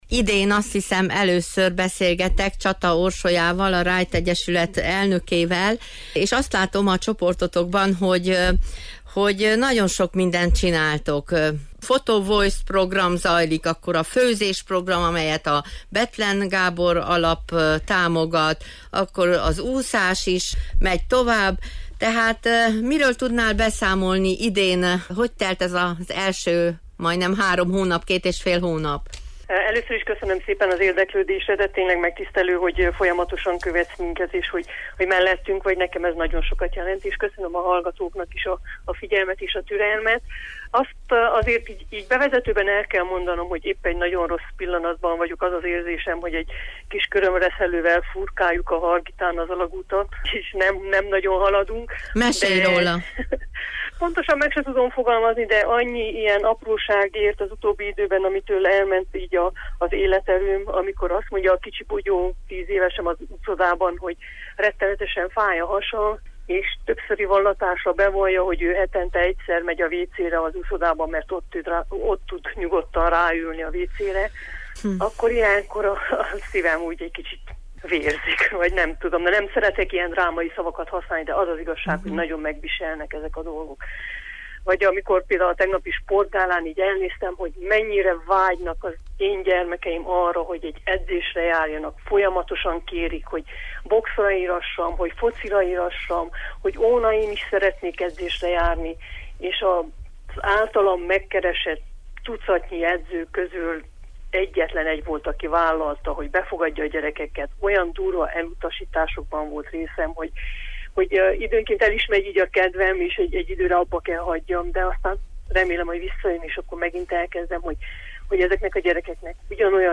Most ismét felkerestük őt telefonon, hiszen a Facebook Csebe nevű csoportjukban láttuk, hogy nagyon sok tevékenység zajlik ott: folytatták a Bethlen Gábor Alap által támogatott főzőtanfolyamot, szerveztek Photovoice programot, (hogy ez mit jelent erről ő maga számol be az interjú során), zajlik a mentorprogram, folytatják az úszó edzést, és ismét elkezdik a futást is, hiszen lassan kitavaszodott.